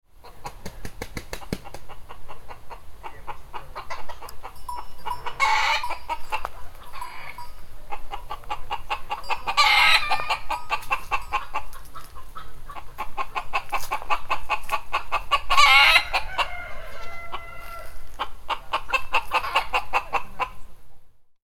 Chicken Sounds: Clucking and Crowing Noises
Description: Chicken sounds clucking, crowing, and farmyard noises. Chicken noises including clucking hens and crowing roosters.
Genres: Sound Effects
Chicken-sounds-clucking-crowing-and-farmyard-noises.mp3